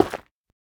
Minecraft Version Minecraft Version latest Latest Release | Latest Snapshot latest / assets / minecraft / sounds / block / netherrack / break4.ogg Compare With Compare With Latest Release | Latest Snapshot
break4.ogg